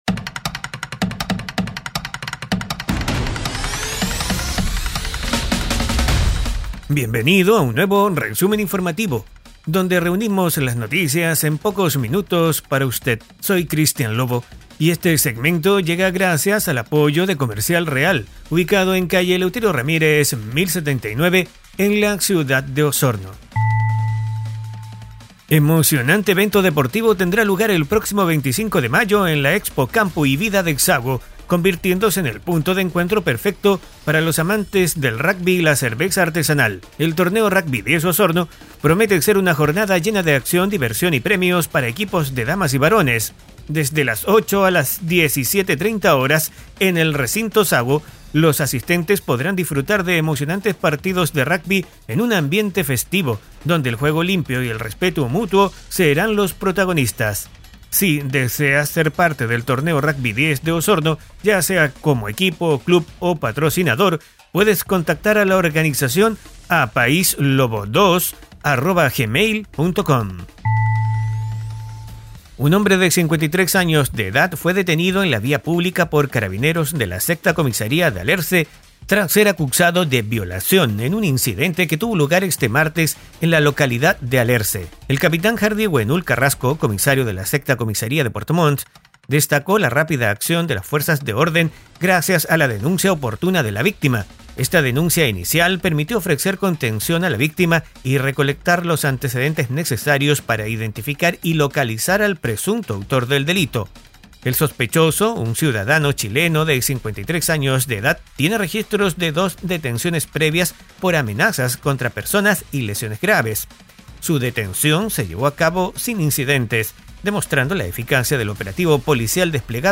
Resumen Informativo 🎙 Podcast 14 de mayo de 2024